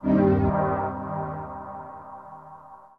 SYNTHPAD002_AMBNT_160_C_SC3(L).wav